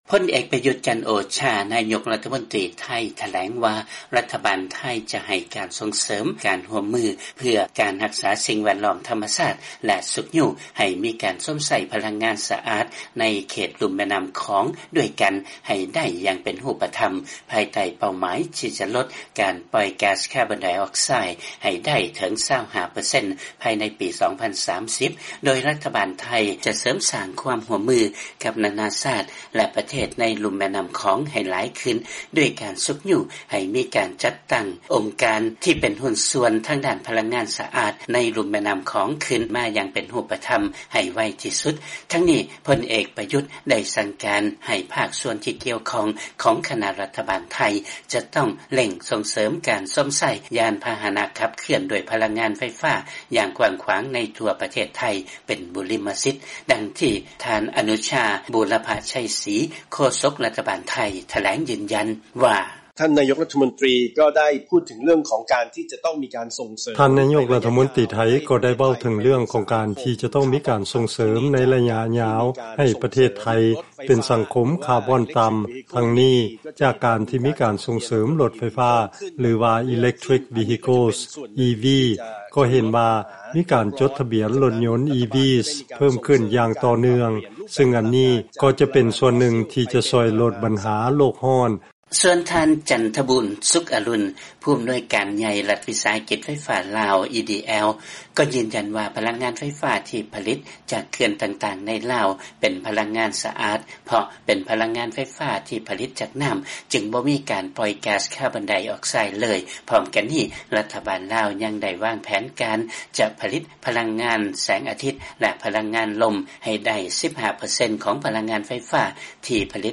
ຟັງລາຍງານ ນາຍົກໄທ ຢືນຢັນວ່າ ຈະຊຸກຍູ້ໃຫ້ມີການຮ່ວມມື ເພື່ອຮັກສາສິ່ງແວດລ້ອມທຳມະຊາດ ແລະສົ່ງເສີມການໃຊ້ພະລັງງານສະອາດ ໃນປະເທດລຸ່ມແມ່ນ້ຳຂອງ